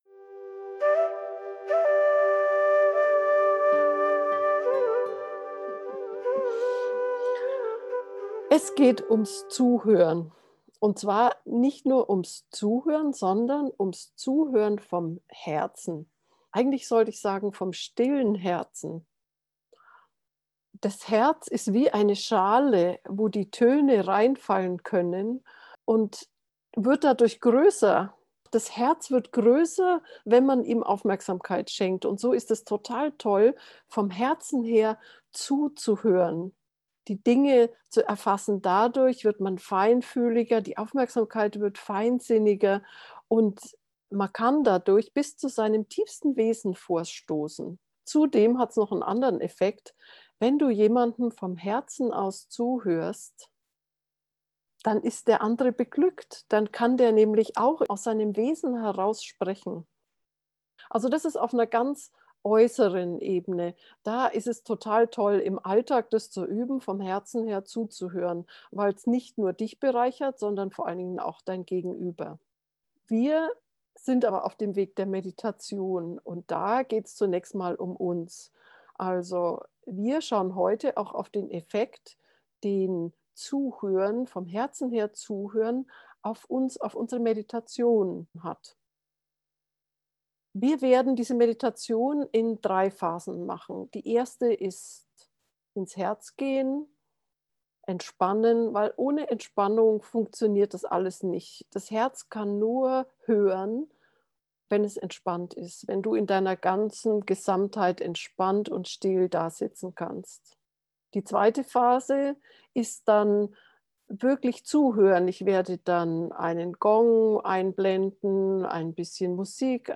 Das geflüsterte Zitat in der Meditation ist von Osho, du kannst es hier nachlesen .
herzmeditation-herz-zuhoeren-gefuehrte-meditation